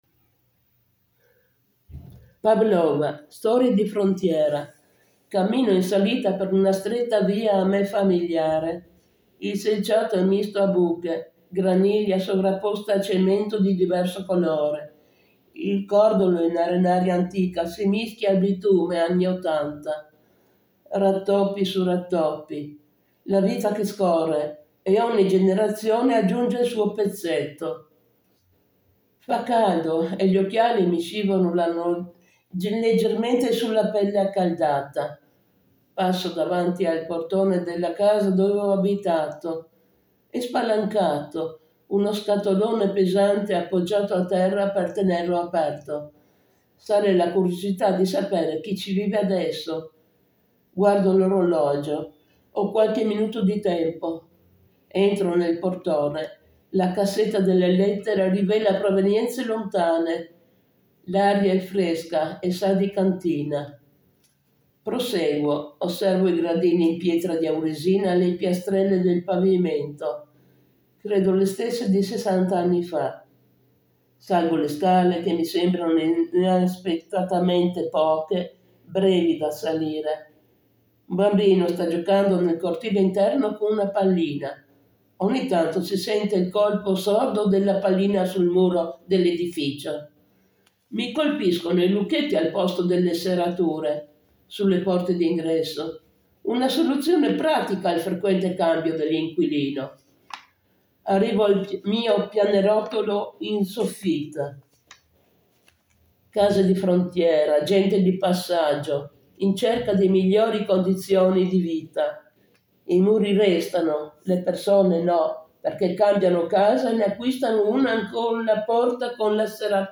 letto in italiano